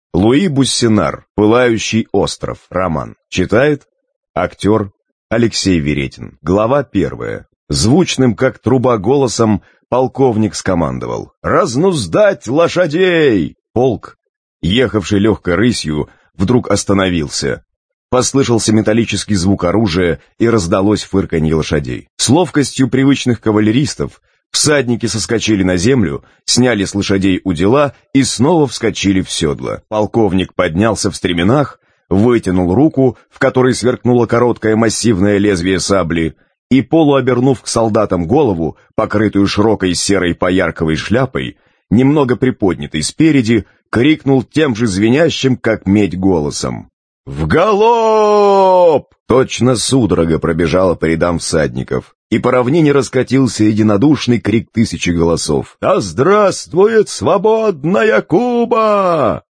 Аудиокнига Пылающий остров | Библиотека аудиокниг